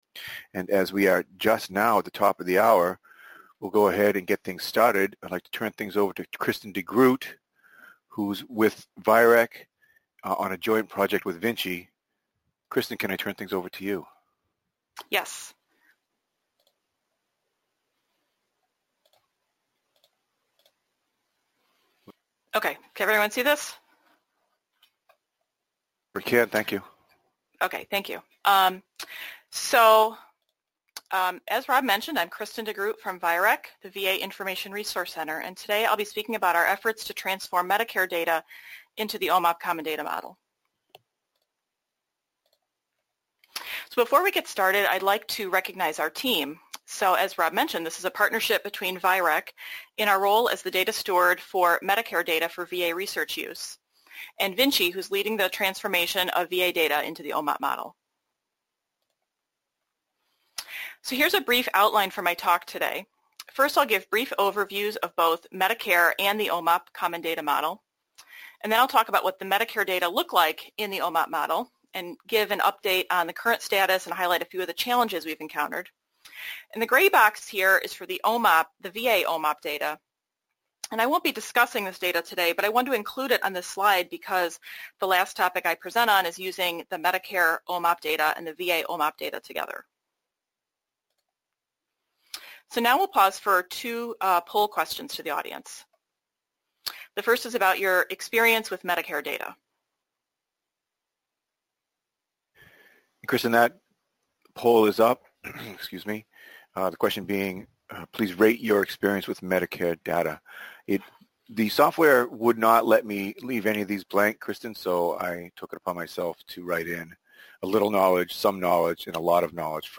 Description: This presentation will describe efforts underway in the VA to transform Medicare data into the OMOP common data model (CDM). We will discuss the benefits to using the Medicare data in this format and the current status of the transformation. We provide examples of how the Medicare and VA data in OMOP format can seamlessly be used together.